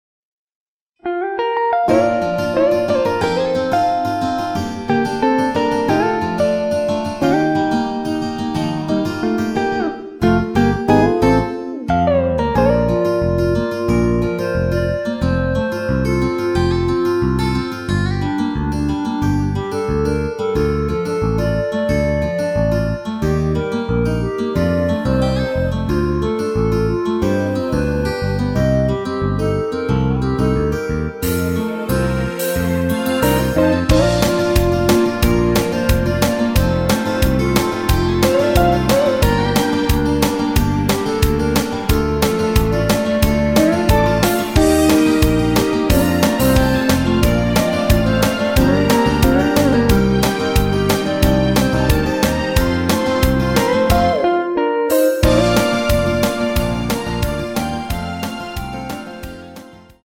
원키 멜로디 포함된 MR 입니다.(미리듣기 참조)
Bb
앞부분30초, 뒷부분30초씩 편집해서 올려 드리고 있습니다.
중간에 음이 끈어지고 다시 나오는 이유는